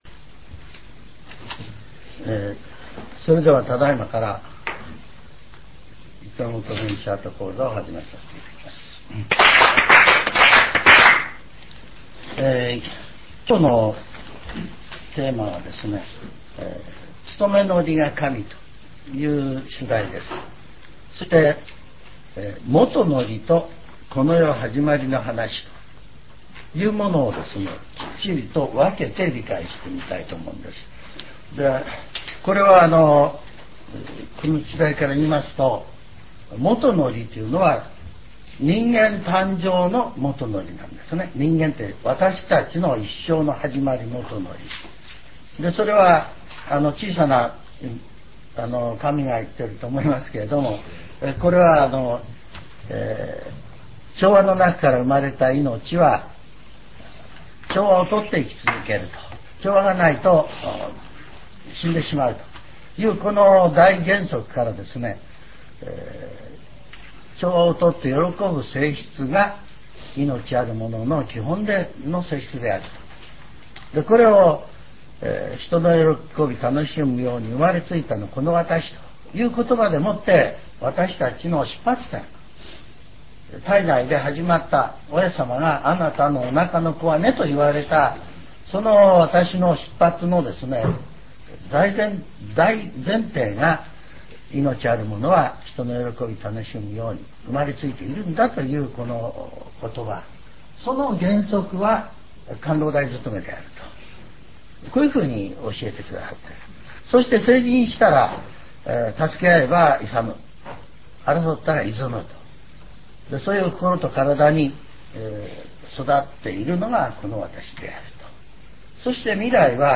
全70曲中3曲目 ジャンル: Speech